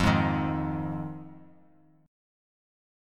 Fm7 Chord
Listen to Fm7 strummed